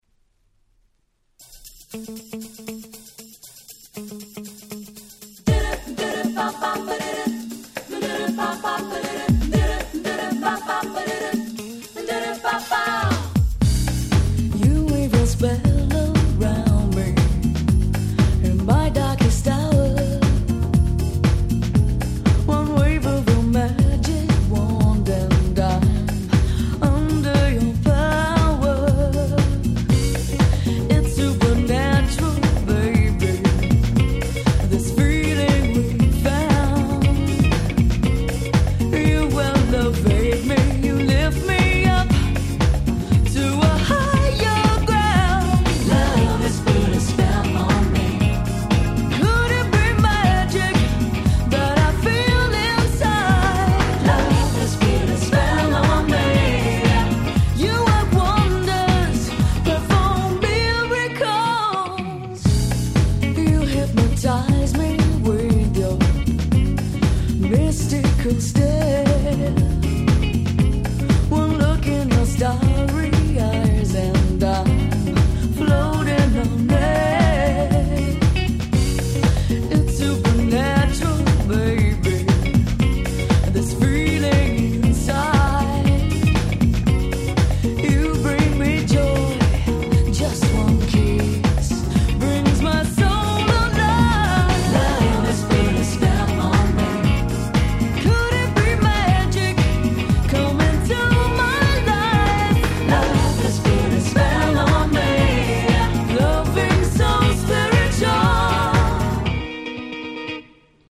93' Nice UK Soul/Acid Jazz !!
BPM速めの超爽快なAcid Jazzチューン！
この疾走感、堪りません！